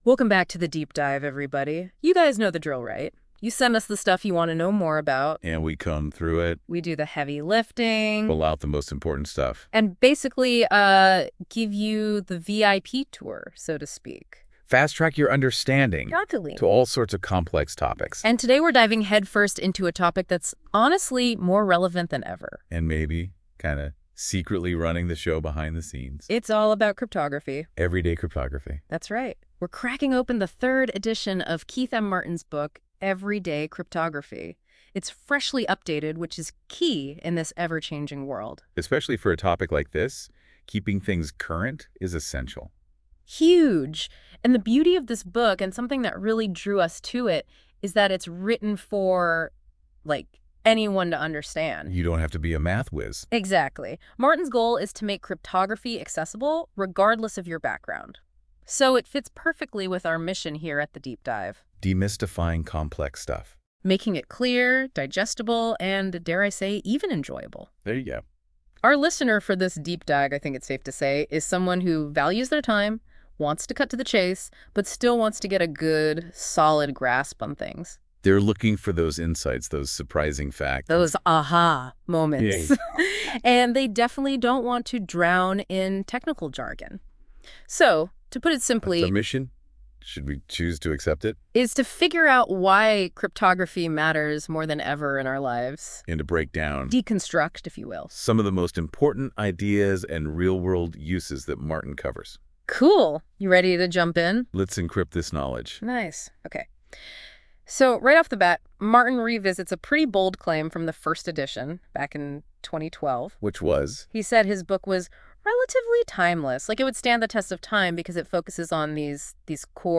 Listen to an AI overview of what's new in Edition 3 !